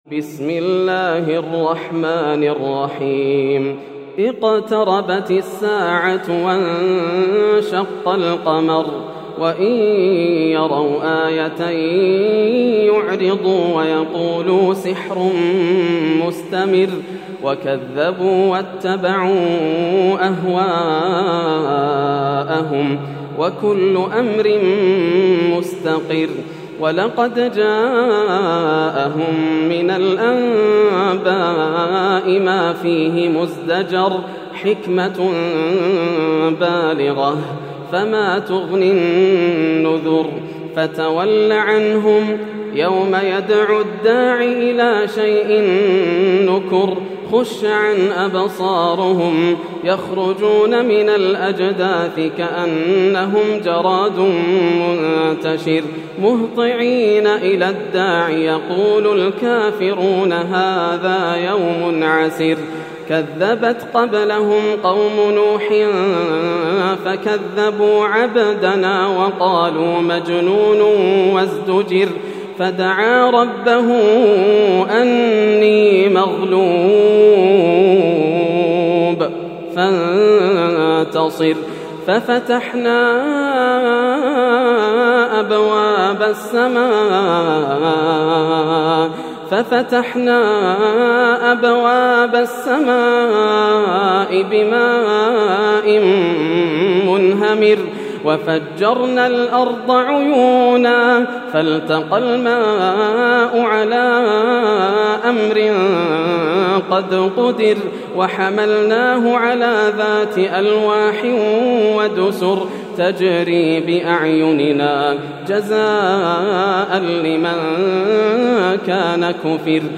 سورة القمر > السور المكتملة > رمضان 1431هـ > التراويح - تلاوات ياسر الدوسري